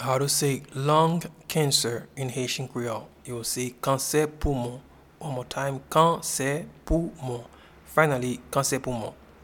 Lung-cancer-in-Haitian-Creole-Kanse-poumon.mp3